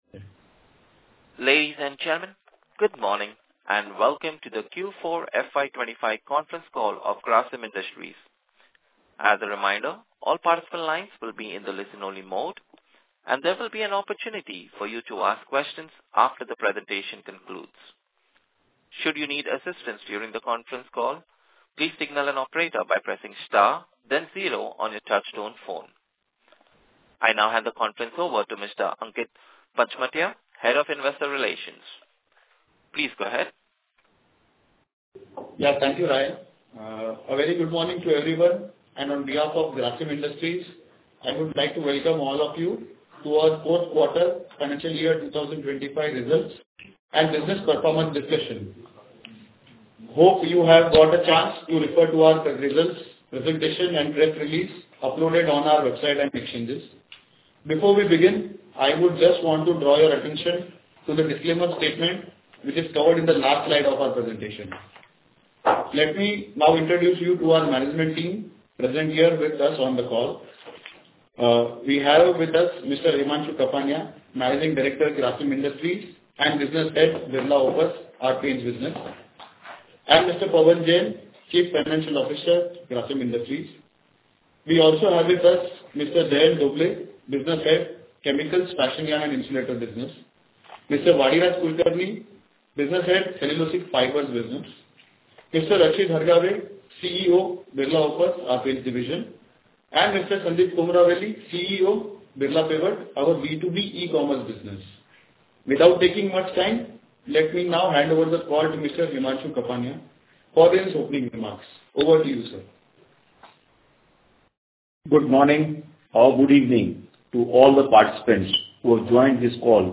Earnings Call Audio
grasim-earnings-call-q4fy25.mp3